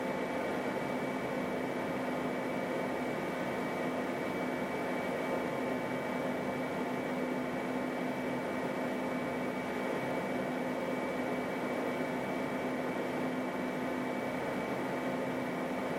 The loudest frequency is 250 Hz, which belongs to the bass region.
I have recorded the signals shown above, but please keep in mind that I’ve enabled Automatic Gain Control (AGC) to do so to make it easier for you to reproduce them.
75% Fan Speed